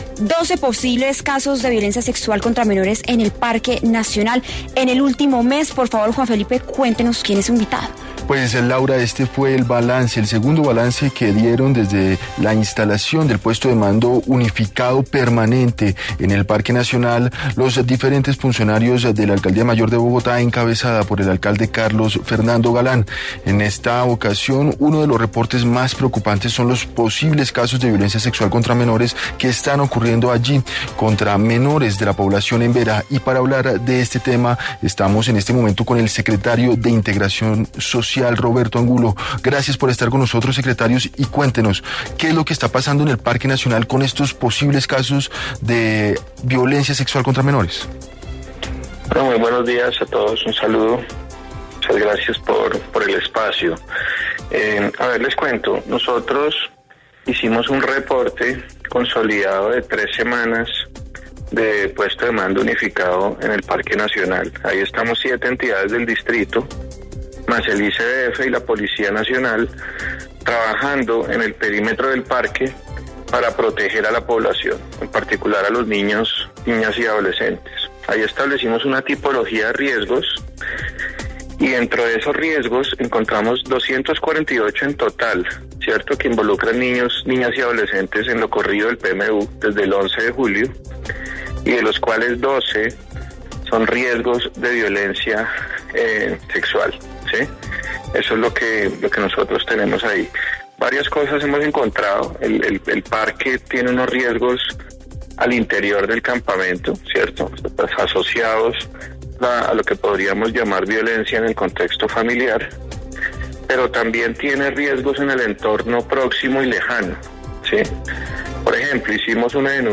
Roberto Angulo, secretario de Integración Social de Bogotá, conversó con W Fin de Semana a propósito de las denuncias de 12 posibles casos de violencia sexual contra menores en el Parque Nacional durante el último mes.
Escuche la entrevista a Roberto Angulo, secretario de Integración Social de Bogotá, en W Fin de Semana: